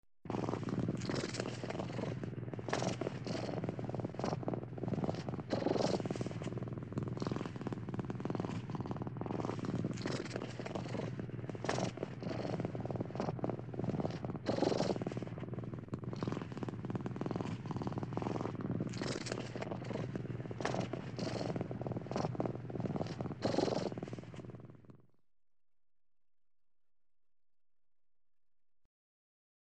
Мурлыкает кошка
Тут вы можете прослушать онлайн и скачать бесплатно аудио запись из категории «Кошки, котята».